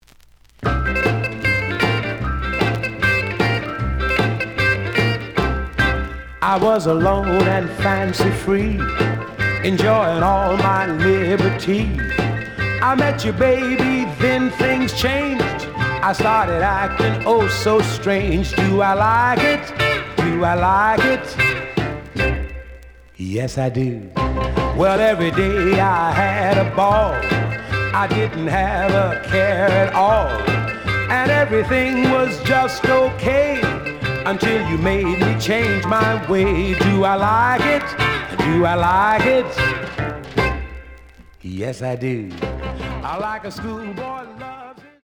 試聴は実際のレコードから録音しています。
The audio sample is recorded from the actual item.
●Genre: Vocal Jazz